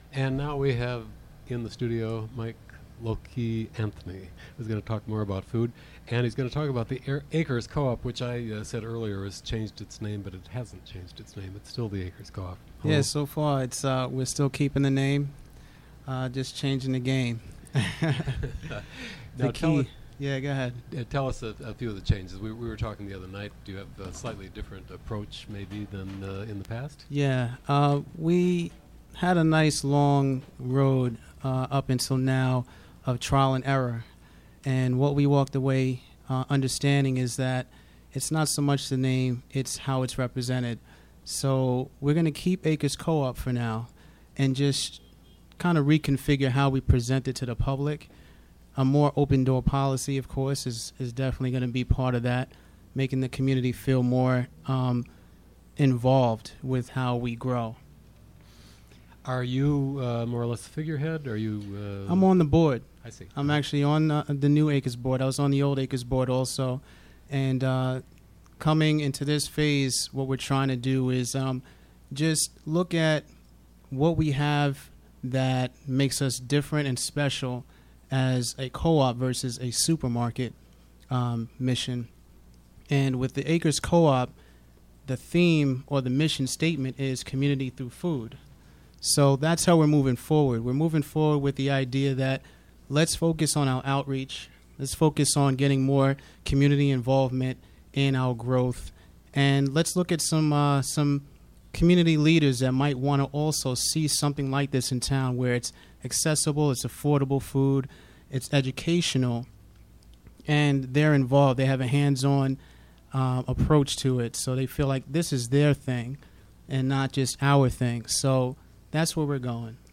PLAY INTERVIEW ABOUT ACRES FOOD CO-OP 10:11